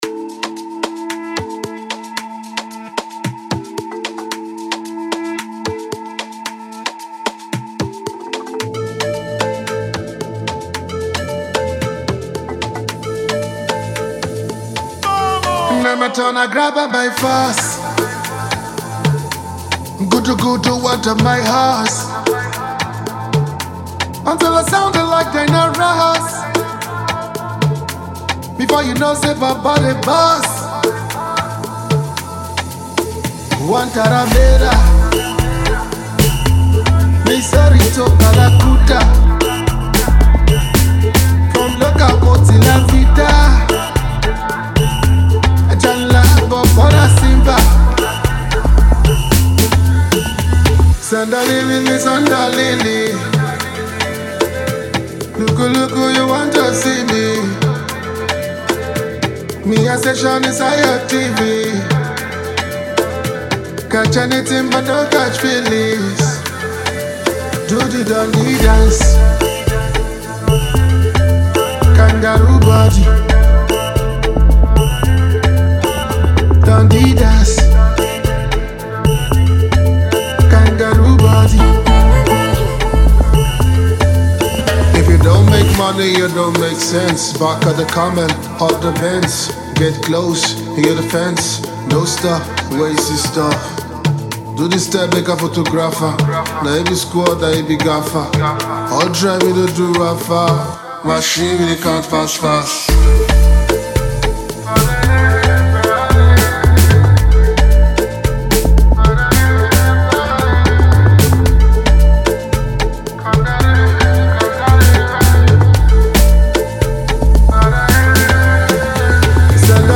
A perfect feel-good vibe for every mood
ampiano infused banger